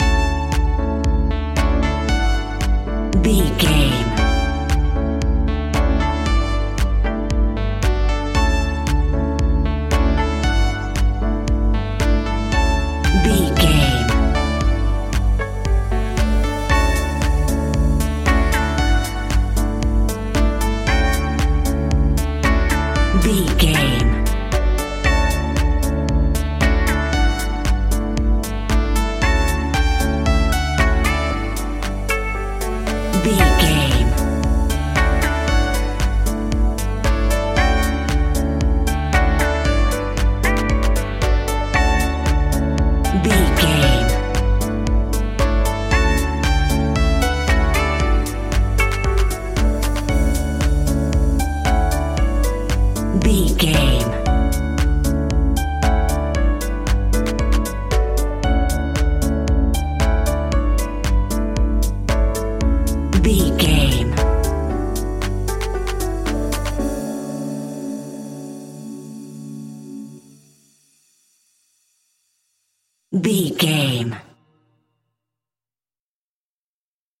Ionian/Major
uplifting
energetic
bouncy
electric piano
drum machine
synthesiser
electro house
funky house
synth bass